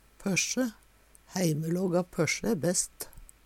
pøssje - Numedalsmål (en-US)